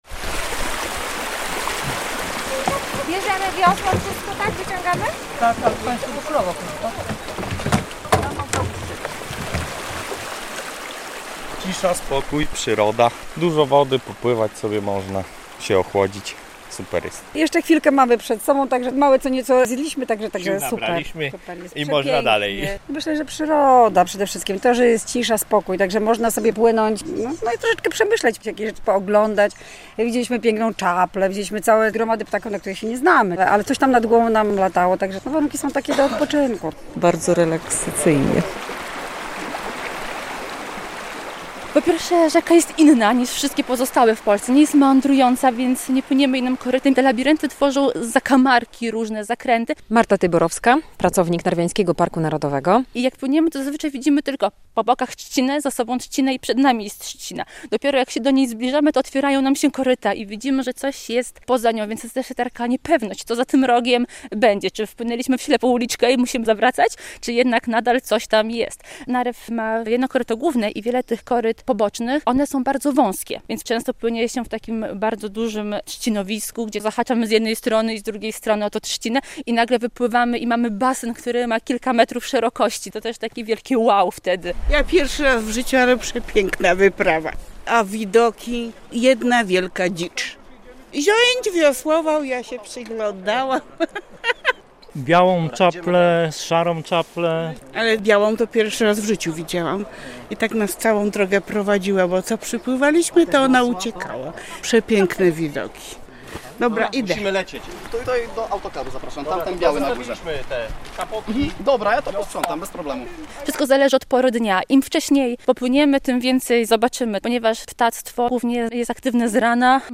Spływy kajakowe w Narwiańskim Parku Narodowym - relacja